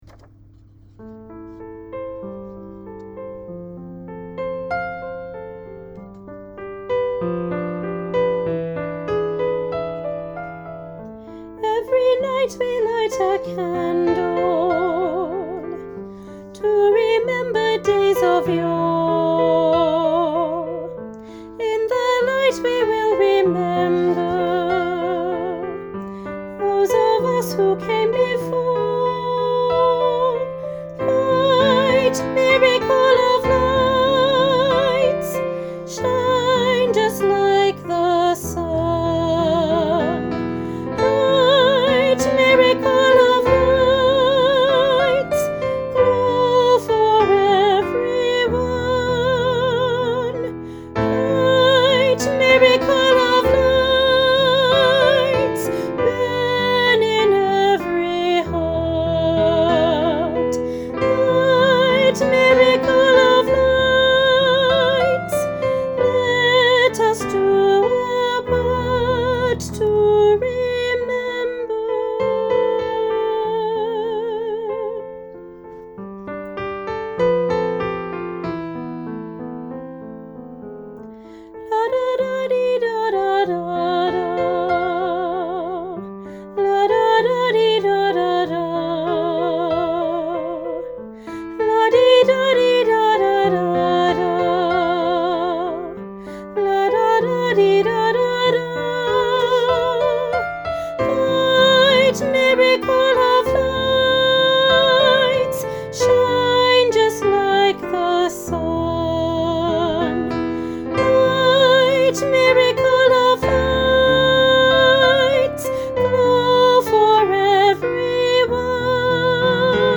Junior Choir – Miracle Of Lights – Top Part